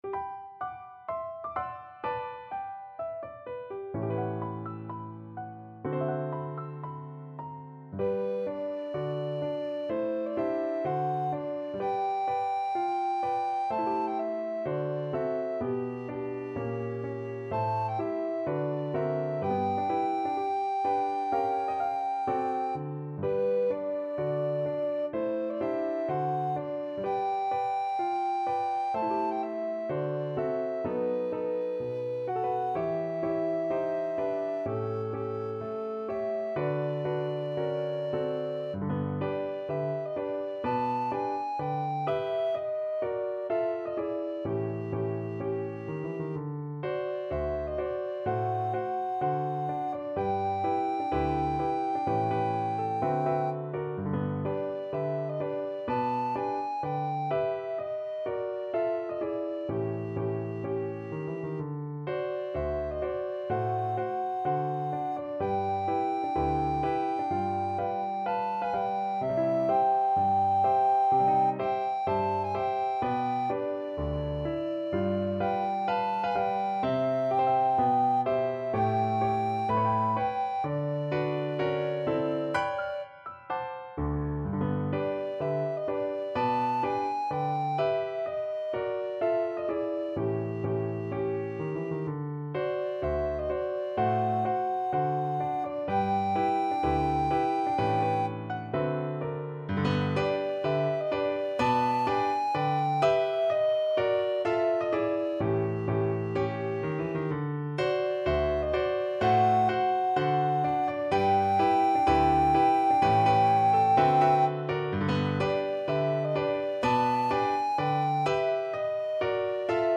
Moderato cantabile =126
2/2 (View more 2/2 Music)
Pop (View more Pop Alto Recorder Music)